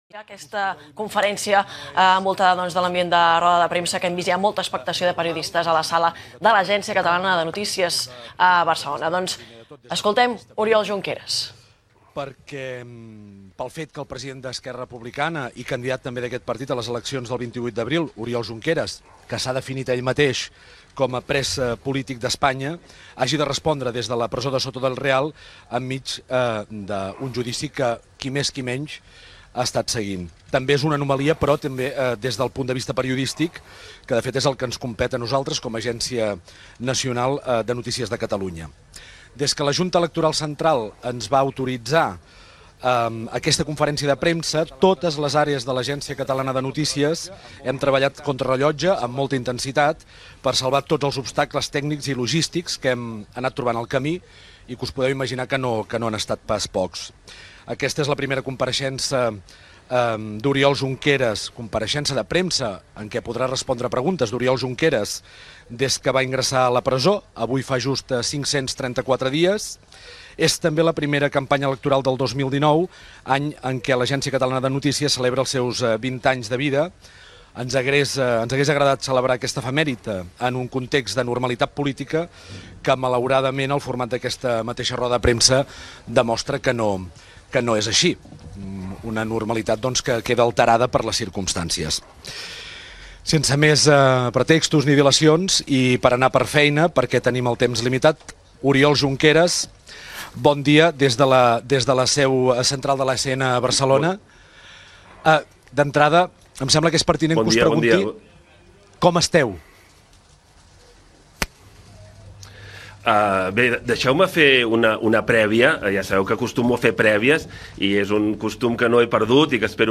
El polític Oriol Junqueras, d'Esquerra Republicana de Catalunya, fa una roda de premsa, des de la presó de Soto del Real, organitzada per l'Agència Catalana de Notícies
Info-entreteniment